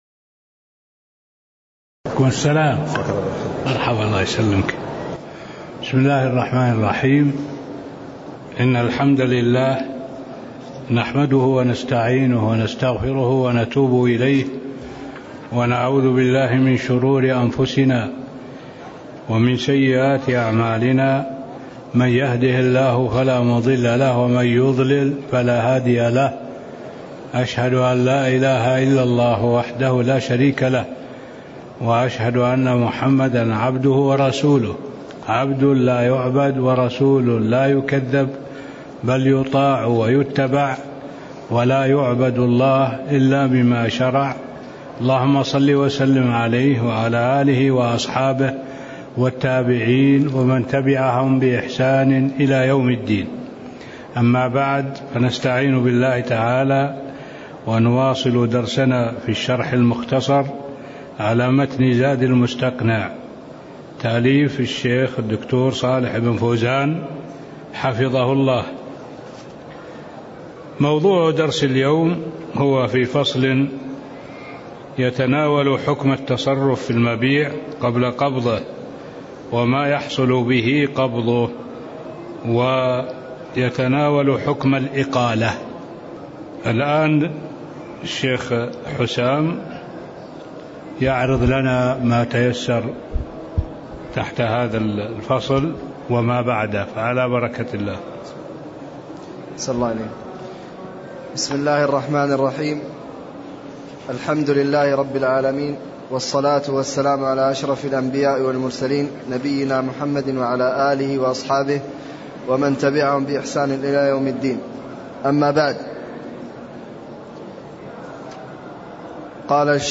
تاريخ النشر ١٨ صفر ١٤٣٥ هـ المكان: المسجد النبوي الشيخ